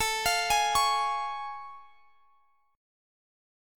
Listen to A+M7 strummed